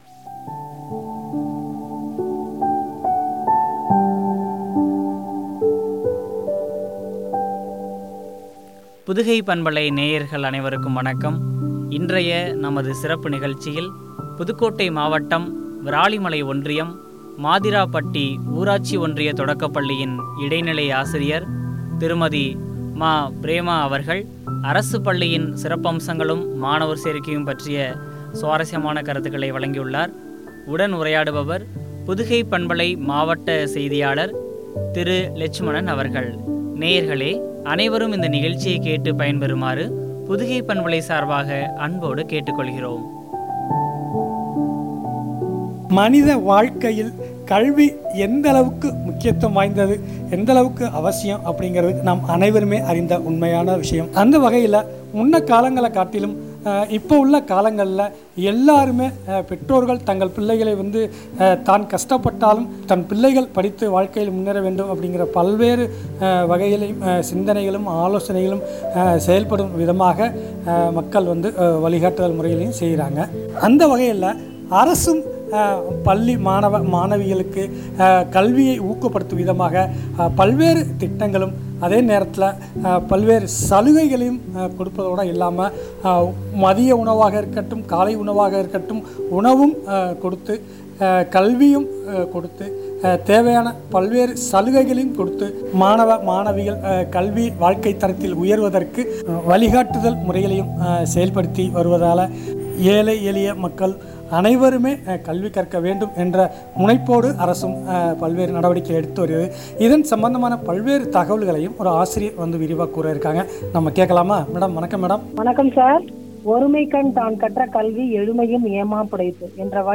அரசு பள்ளியின் சிறப்பம்சங்களும், மாணவர் சேர்க்கையும் குறித்து வழங்கிய உரையாடல்.